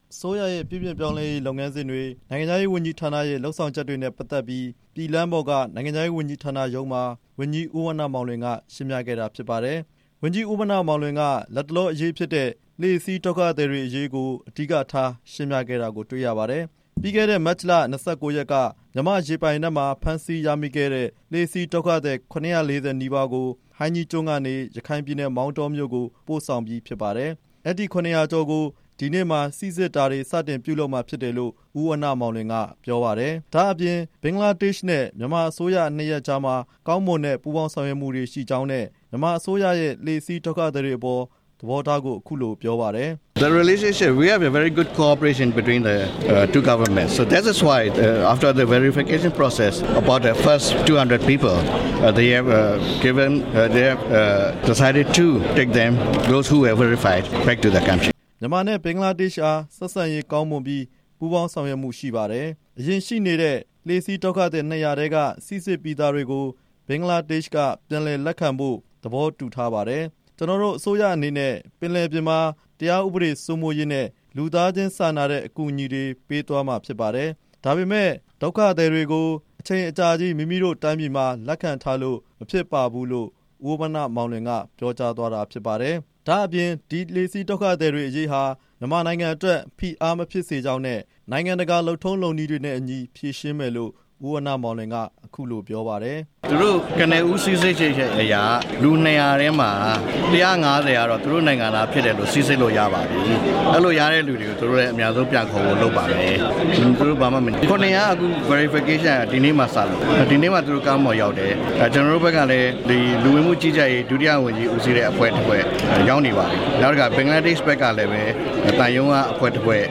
လှေစီးဒုက္ခသည်တွေအရေး နိုင်ငံခြားရေးဝန်ကြီးရုံးက သတင်းစာရှင်းလင်းပွဲ
မြန်မာနိုင်ငံရဲ့ ပြုပြင်ပြောင်းလဲရေးလုပ်ငန်းစဉ်တွေနဲ့ လက်ရှိကယ်ဆယ်ထားတဲ့ လှေစီးဒုက္ခသည်တွေ အရေးကိစ္စကို နိုင်ငံခြားရေးဝန်ကြီး ဦးဝဏ္ဏမောင်လွင်နဲ့ တာဝန်ရှိသူတွေက ဒီနေ့ ရန်ကုန်မြို့ နိုင်ငံခြားရေးဝန်ကြီးရုံးမှာ သတင်းစာရှင်းလင်းပွဲကျင်းပပြီး ရှင်းပြခဲ့ပါတယ်။
သတင်းထောက်တွေကလည်း လှေစီးဒုက္ခသည်တွေအရေးနဲ့ပတ်သက်ပြီး မြန်မာအစိုးရရဲ့ သဘောထားကို မေးမြန်းခဲ့ပါတယ်။